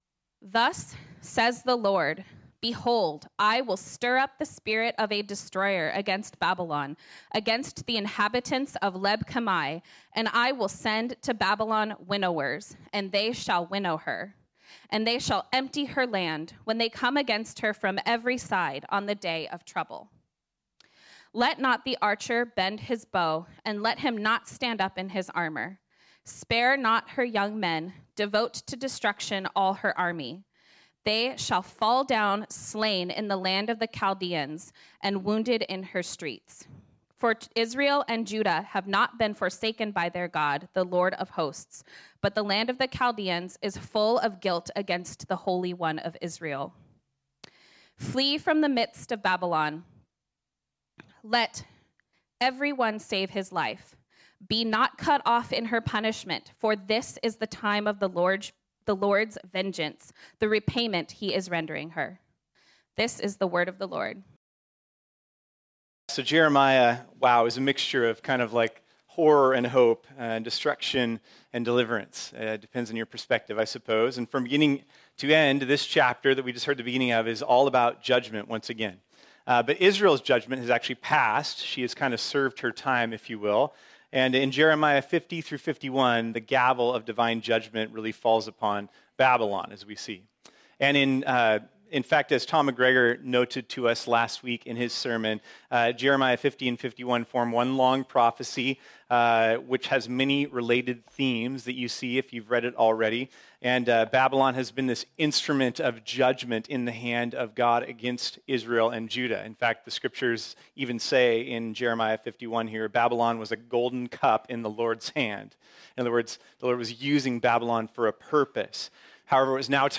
sermon
This sermon was originally preached on Sunday, August 28, 2022.